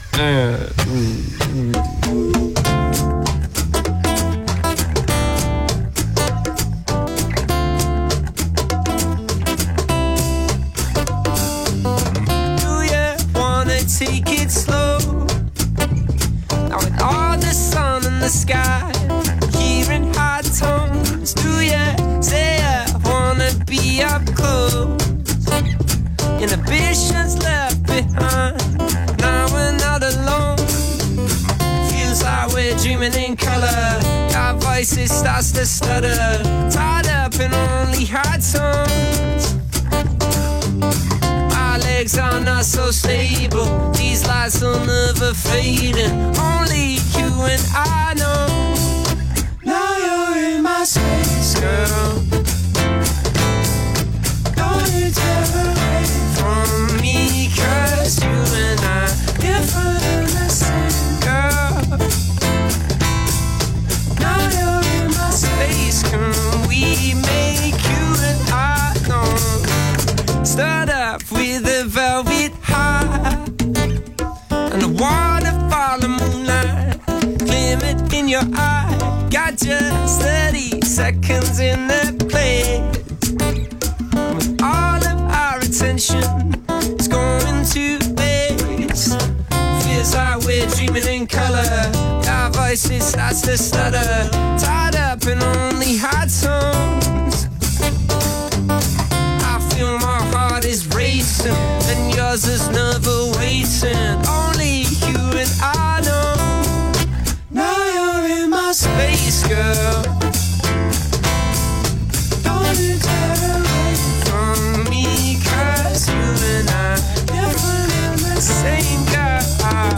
Indie with a Tropical Twist.
recorded live on August 25th
vocals, guitar
bass
drums
a soothing dose of Tropical Pop